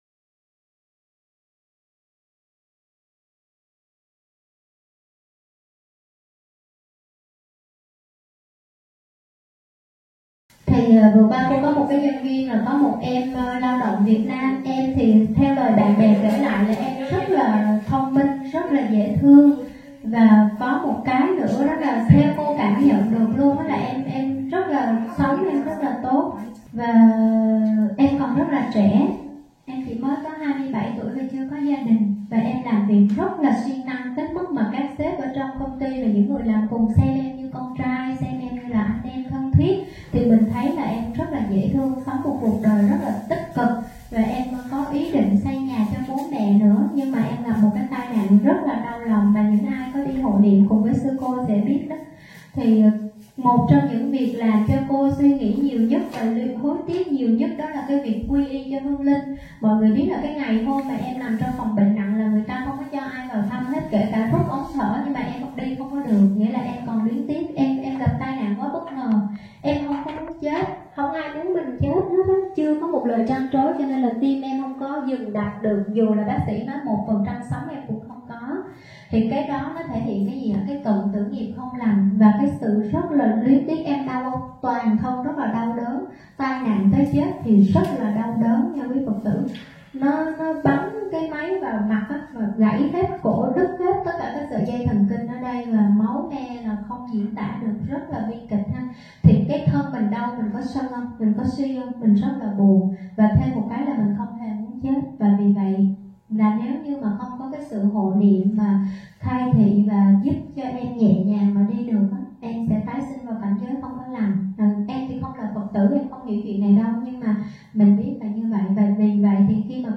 Thuyết pháp Phước đức mang trọn kiếp người